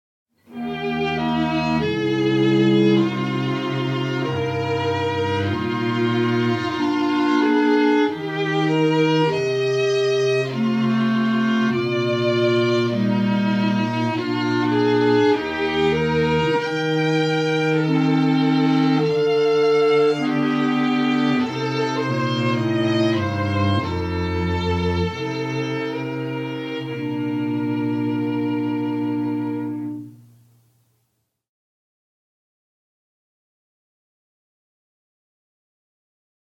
Violin, Clarinet, Cello:  7,